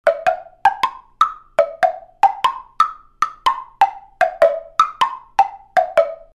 LP Granite Blocks are the modern version of the ancient Chinese temple blocks.
Crafted from Jenigor, LP's exclusive plastic formulation, LP Granite Blocks are a set of five virtually indestructible temple blocks that are tuned to match the pitch of traditional Chinese temple blocks.